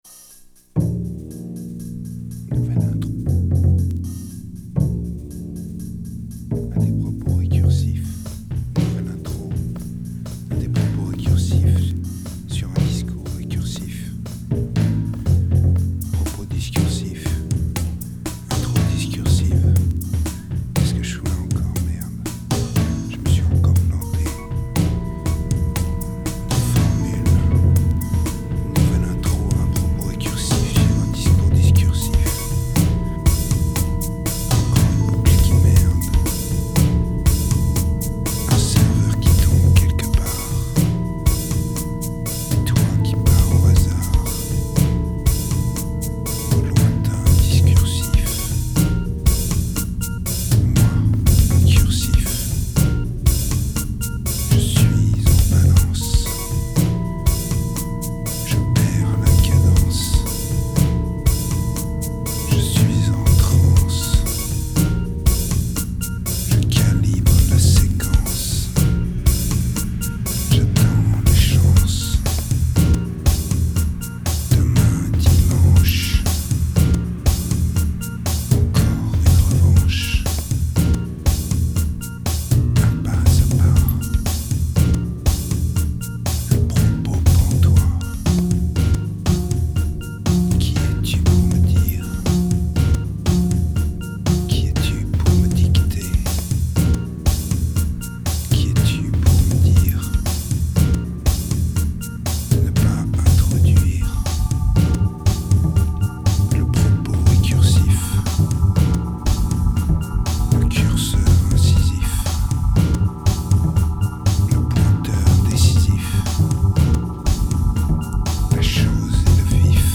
turned out as yet another dirty electro release.